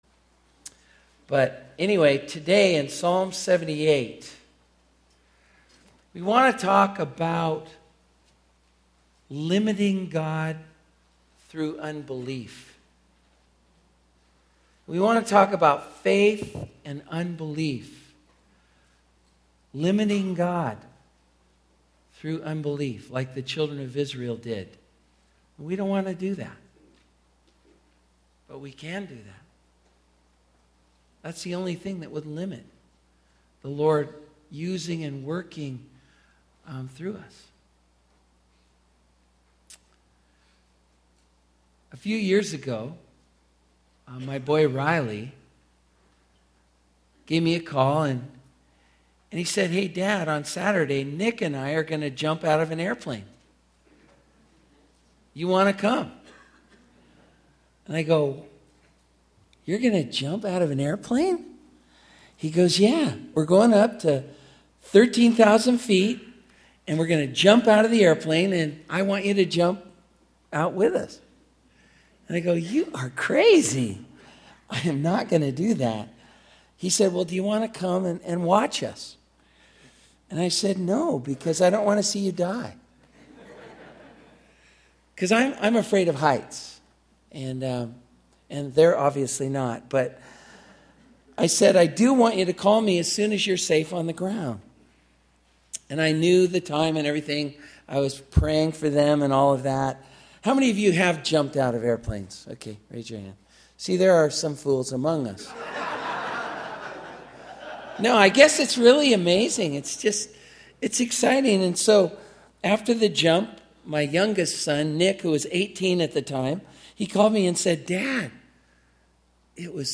2011 Home » Sermons » Session 7 Share Facebook Twitter LinkedIn Email Topics